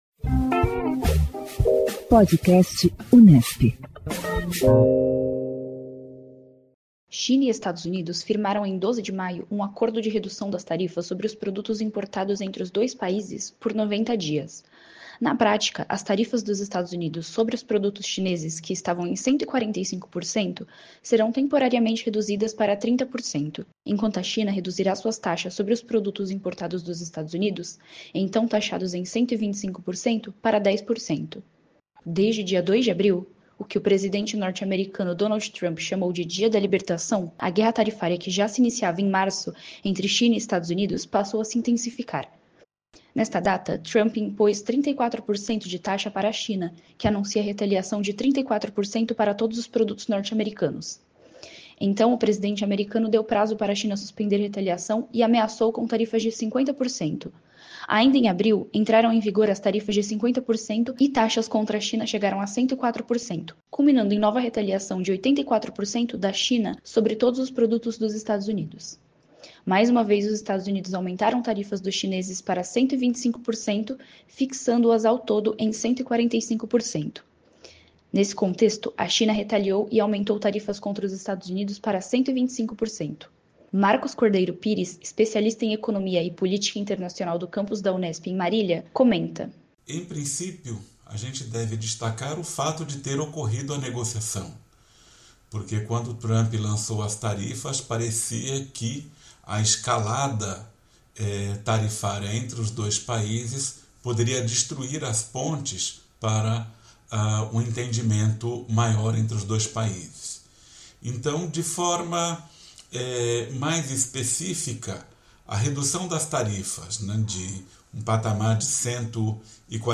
O Podcast Unesp divulga semanalmente entrevistas com cientistas políticos sobre as mais variadas pautas que englobam o universo político e as relações internacionais.